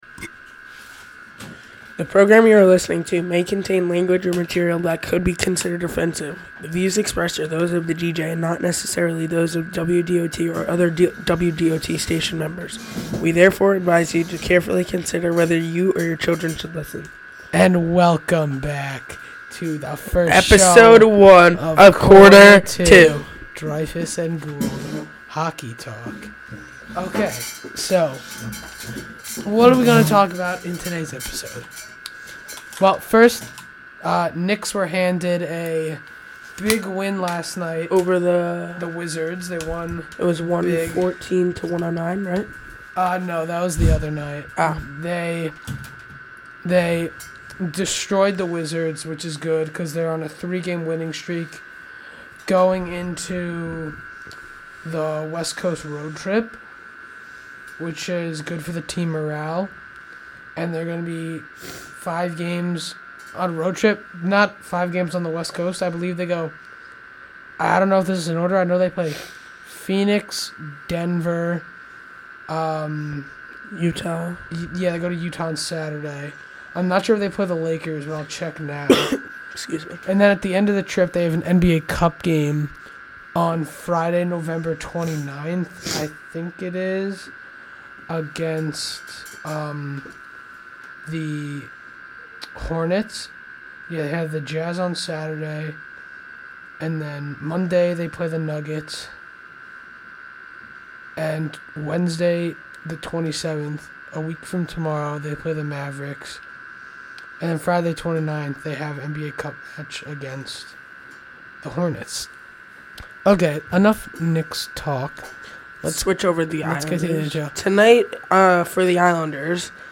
Sports Talk Click here to listen This on-demand broadcast does not violate the US Copyright Law. Music used is incidental or background clips, in accordance of 37 CFR 380.2. of the US Copyright Law.